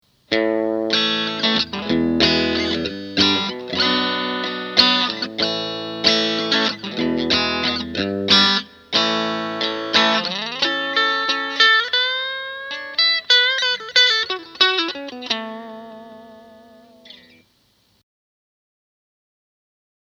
In any case, here are six versions of the same phrase with each different configuration:
Bridge 1